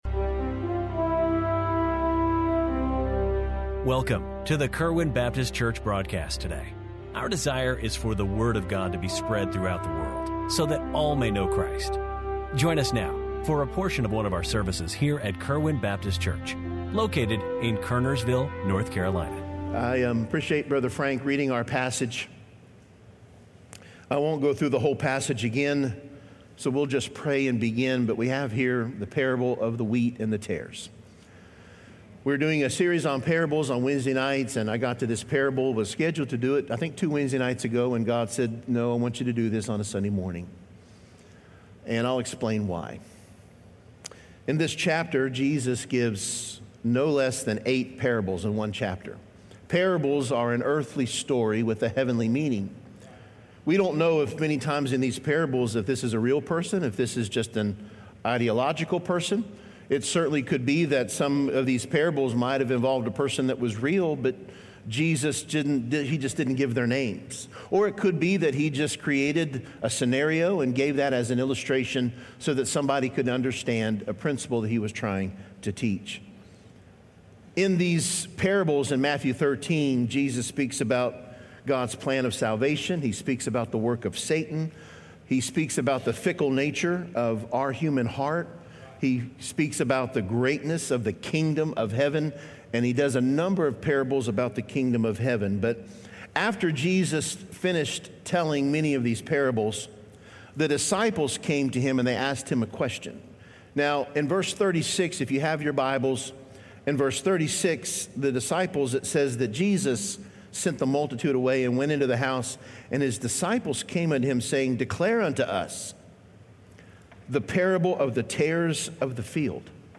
1-1-26 Kerwin Baptist Church Daily Sermon Broadcast